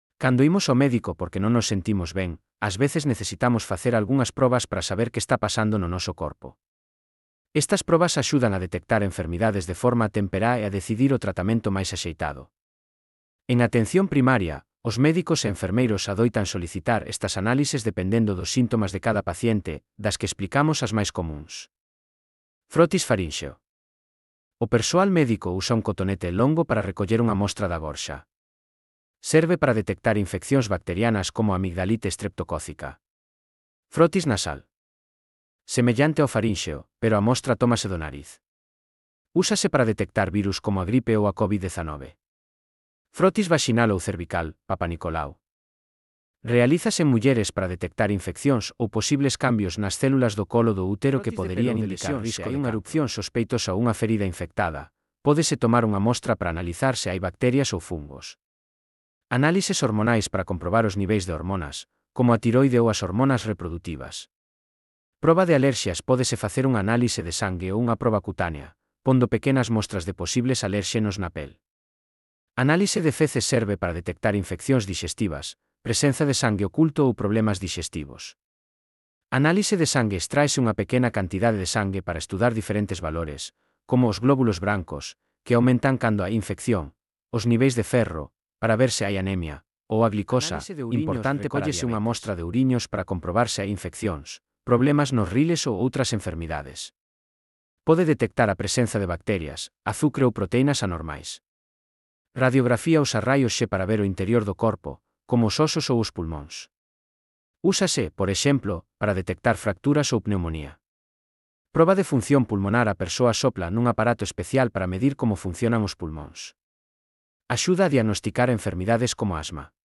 Elaboración propia coa ferramenta Narakeet. Transcrición de texto a audio (CC BY-SA)